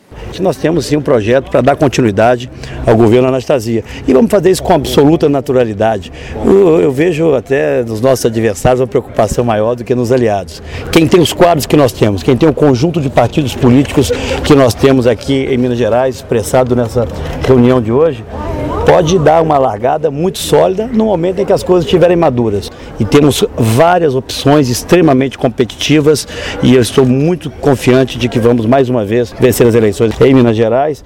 Fala do senador Aécio Neves